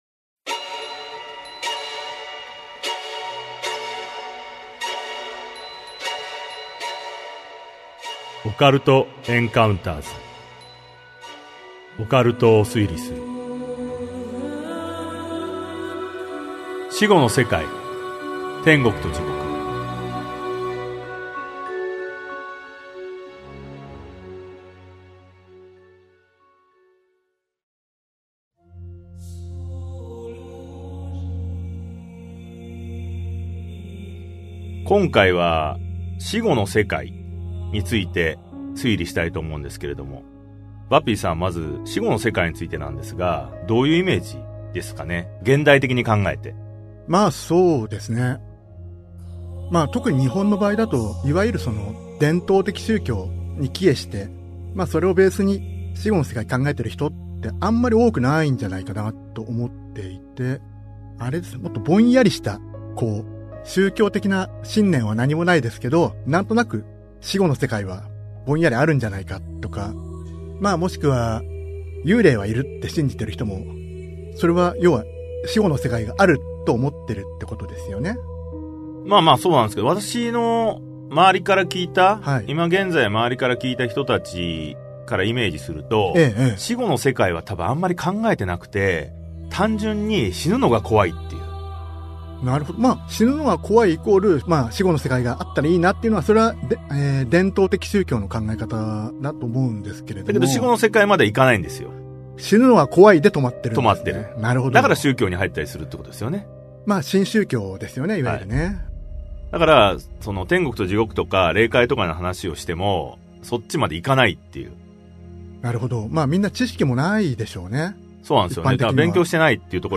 [オーディオブック] オカルト・エンカウンターズ オカルトを推理する Vol.11 死後の世界・天国と地獄編